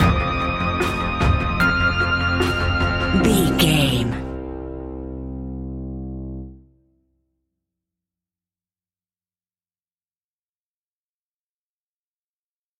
Big Atmospheric Suspense Stinger.
Aeolian/Minor
ominous
dark
eerie
synthesiser
drums
horror music